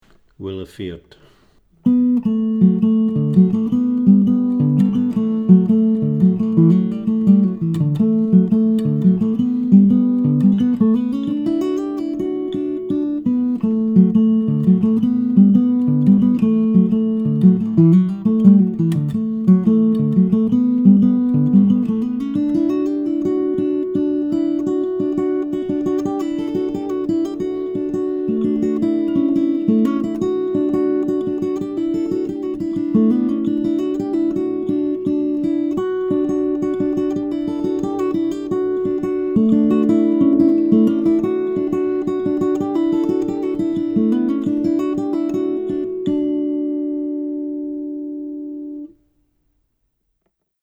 DIGITAL SHEET MUSIC - FINGERPICKING GUITAR SOLO